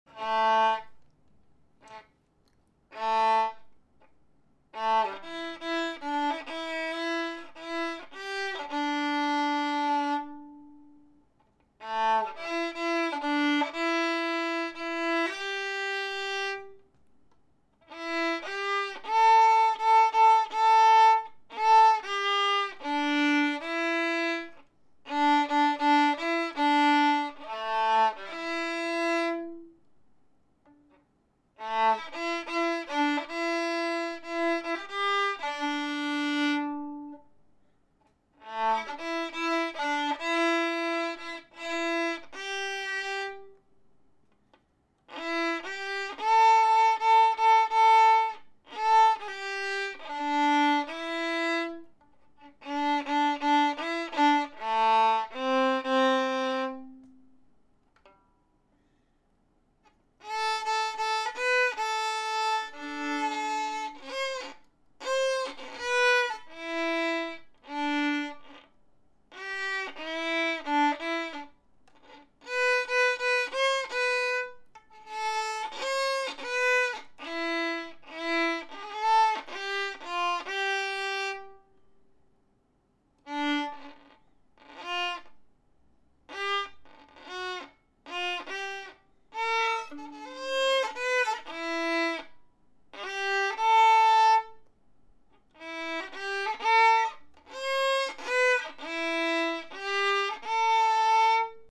Here's a quick record. I just quickly read off some music while I had a little time.
Violin still feels pretty weird to me.